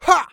XS普通3.wav 0:00.00 0:00.34 XS普通3.wav WAV · 29 KB · 單聲道 (1ch) 下载文件 本站所有音效均采用 CC0 授权 ，可免费用于商业与个人项目，无需署名。
人声采集素材